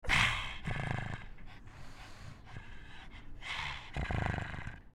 Звуки мопса
Хрипящий звук дыхания мопса